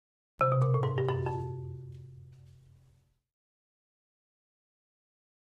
Marimba, Accent, "Stairs" Type 3 - Down, Short, Lower